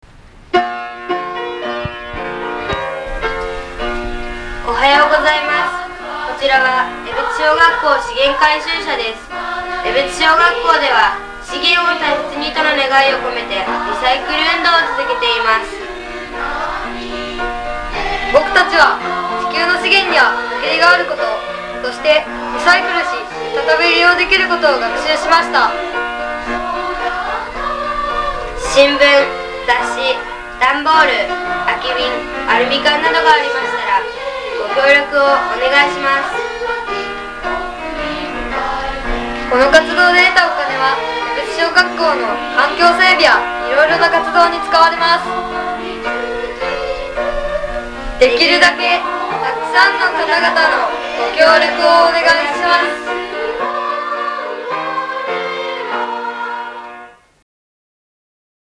江別小学校の校歌を流しながら（２００６年３月から新しいテープになりました）、８時３０分から巡回します。